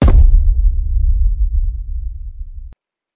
rainbowblue_step.wav